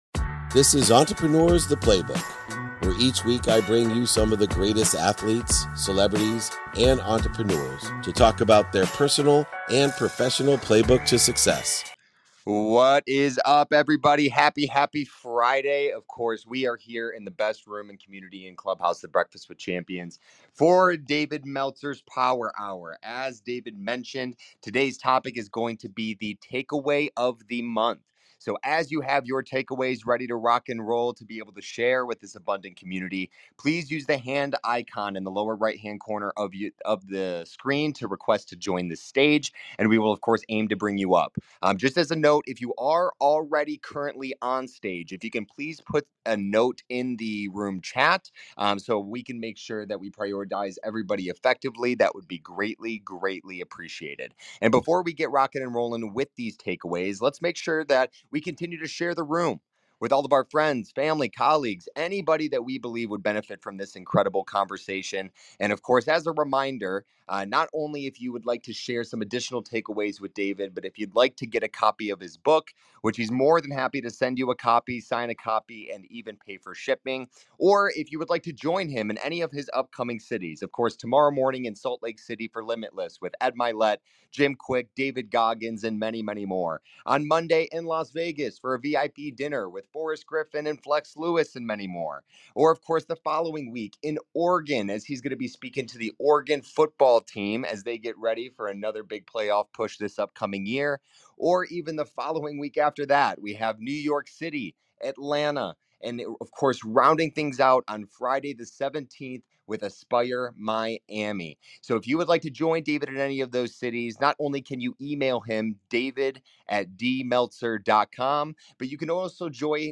In today's episode, I explore the rich insights from our community's monthly takeaways, focusing on themes like honest self-reflection, the impact of persistence, and the significance of consistency in personal and professional life. Hear from a variety of voices as they discuss overcoming challenges, embracing opportunities, and the lessons learned from maintaining an open mind towards growth and community support.